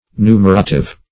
Numerative \Nu"mer*a*tive\, a.